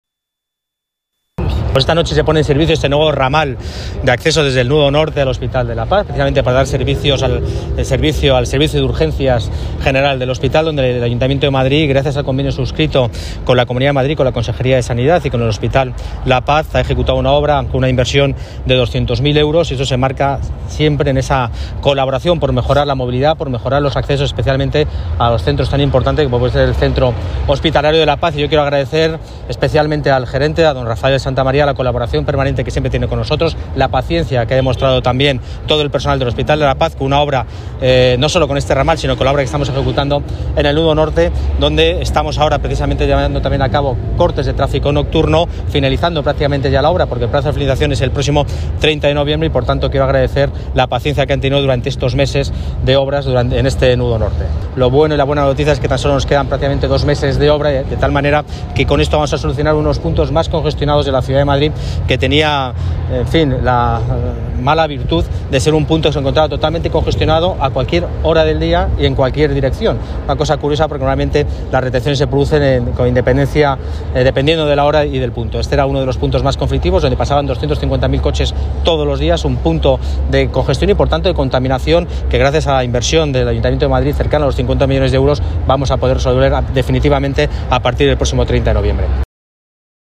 Nueva ventana:Intervención del delegado de Medio Ambiente y Movilidad, Borja Carabante